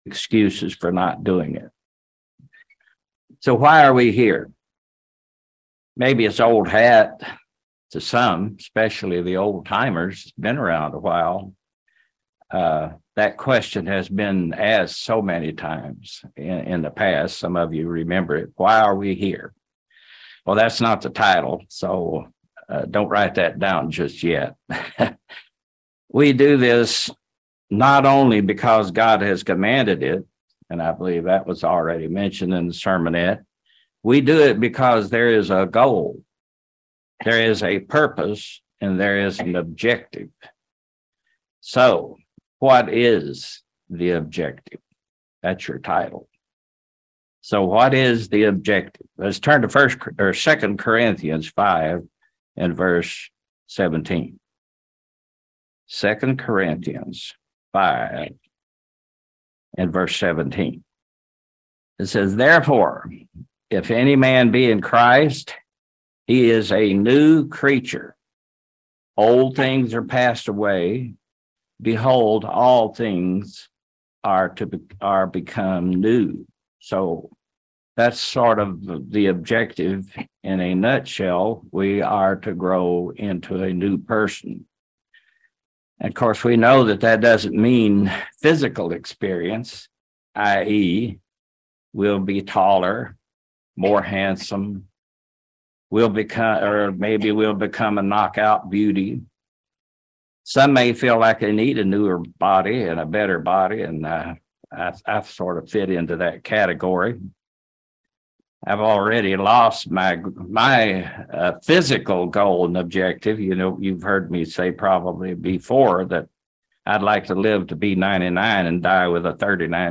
Sermons
Given in London, KY